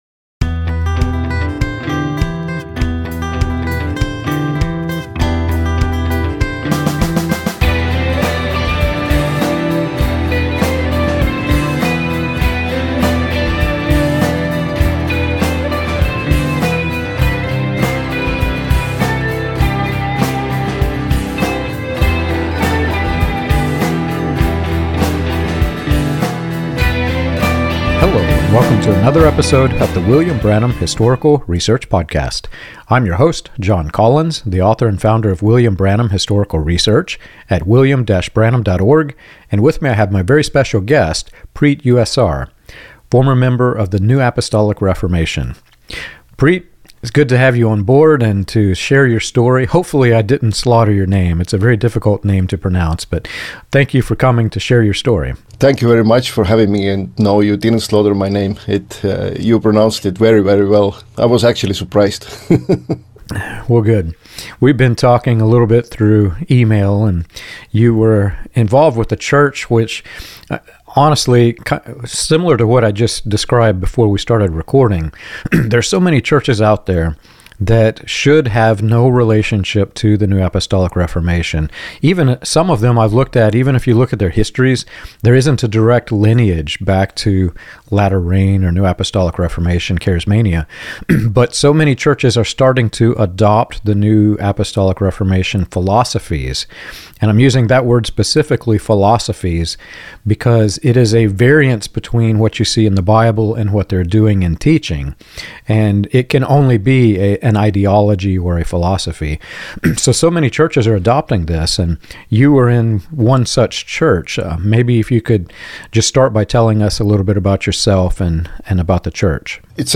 This conversation highlights how people can recognize unhealthy dynamics, rebuild their faith, and find hope on the other side.